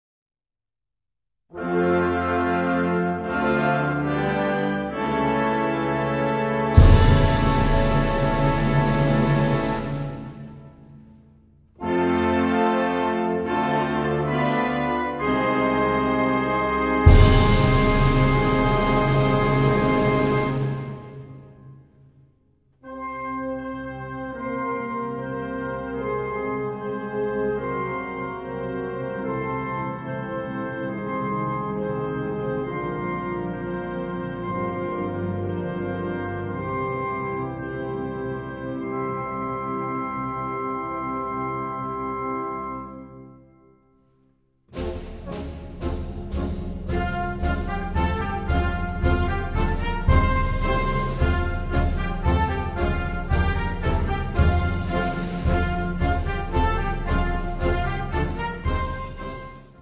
Categoría Banda sinfónica/brass band
Subcategoría Obertura (obra original)
Instrumentación/orquestación Ha (banda de música)